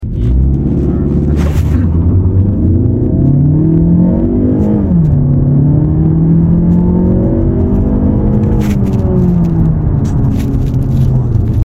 关防滑关启停左脚右脚弹射起步
发动机声音大 但是相比丰田的M20是蛮浑厚的
上传个录音听一下创驰蓝天发动机嘶吼。